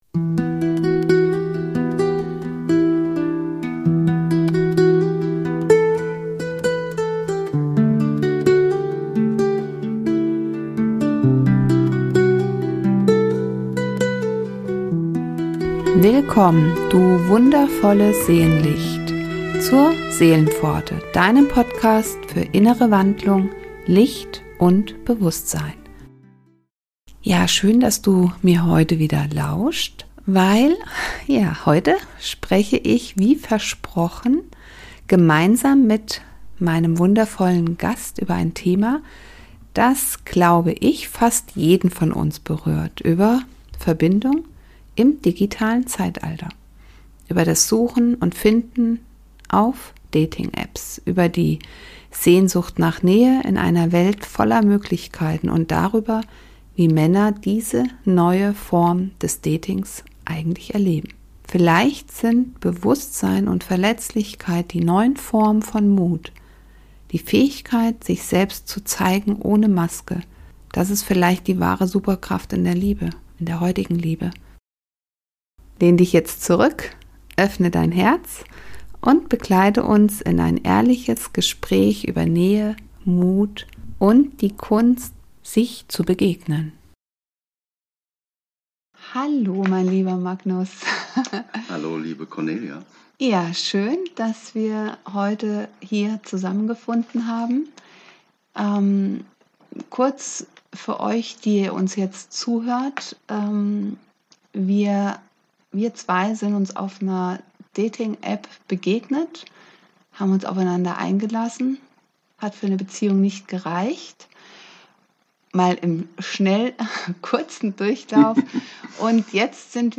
Zwischen Swipe und Sehnsucht - ein Gespräch über Verbindung im digitalen Zeitalter ~ Seelenpforte Podcast